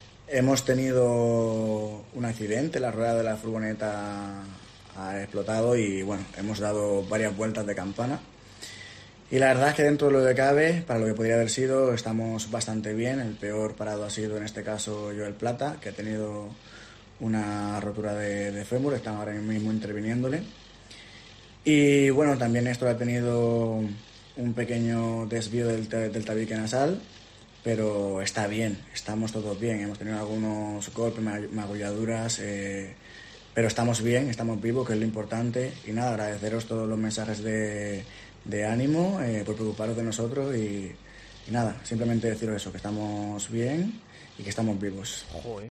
Ray Zapata cuenta cómo ha sido el accidente de tráfico sufrido por el equipo español de gimnasia masculina